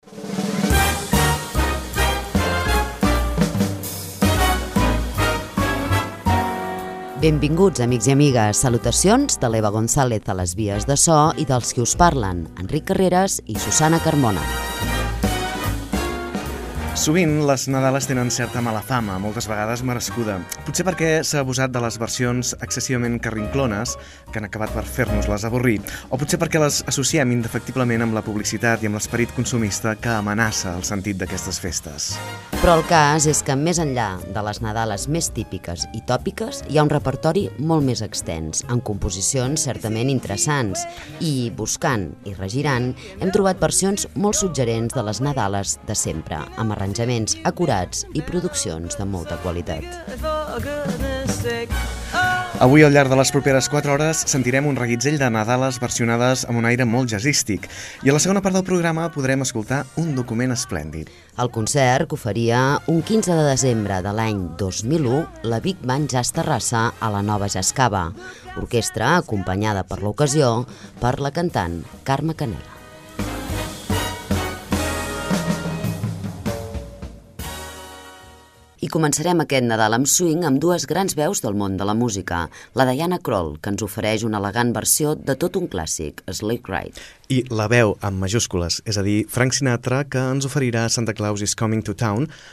Presentació i nadales versionades per intèrprets del jazz
Musical